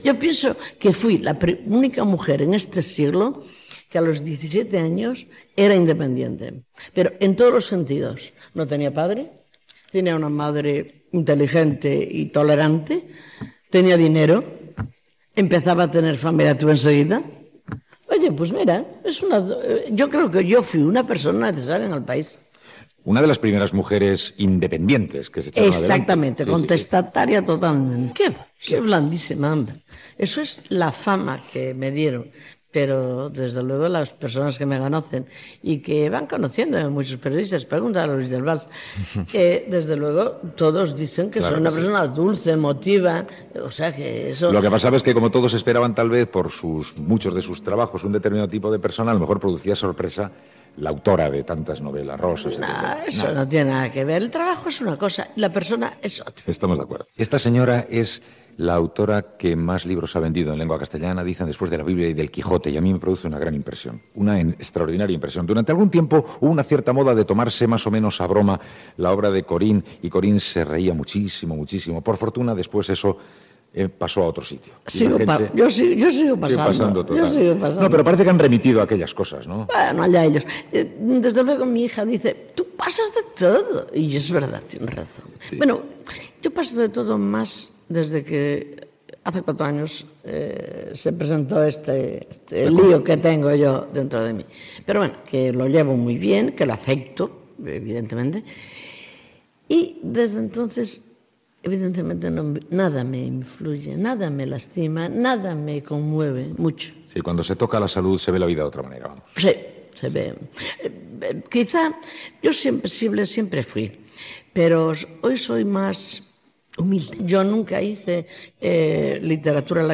Entrevista a l'escriptora Corín Tellado
Info-entreteniment